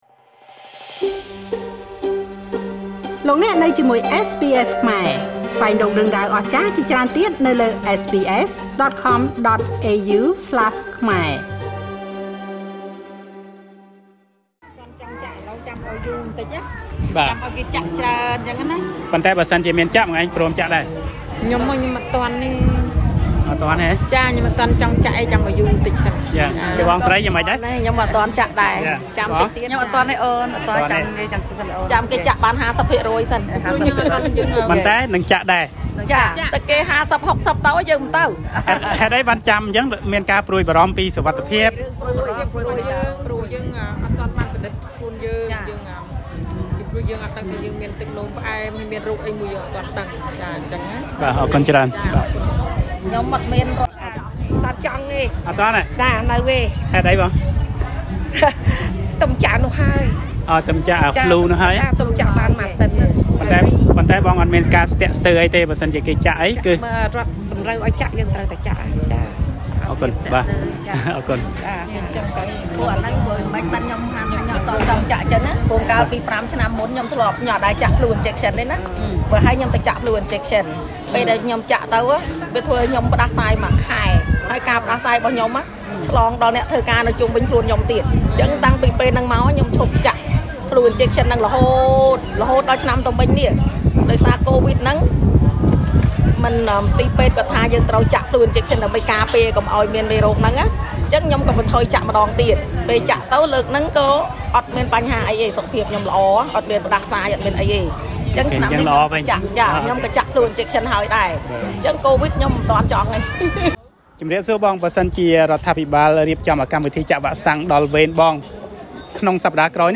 ខ្ញុំបានសាកសួរពលរដ្ឋមួយចំនួនដែលបានមកចូលរួមពិធីបុណ្យផ្កានៅវត្តពុទ្ធរង្ស៊ីកាលពីចុងសប្តាហ៍កន្លងទៅនេះទាក់ទងនឹងការចាក់វ៉ាក់សាំងនេះ ដោយពលរដ្ឋសុទ្ធតែសម្រេចចិត្តថានឹងចាក់វ៉ាក់សាំងពេលដល់វេនពួកគាត់។ ជាមួយគ្នានេះដែរពលរដ្ឋមួយចំនួនក៏បានបង្ហាញនូវការព្រួយបារម្ភពីសុវត្ថិភាពផ្ទាល់ខ្លួនផងដែរ។ តទៅនេះសូមស្តាប់មតិរបស់ពលរដ្ឋតាមការសាកសួររបស់ខ្ញុំបាទដូចទៅ ៖